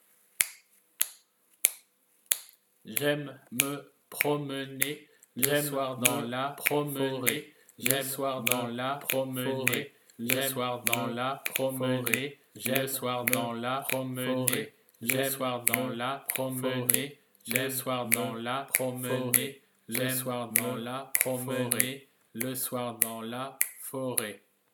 Rythmes sur table
Canon sans décalage :
canon_sans_decalage.mp3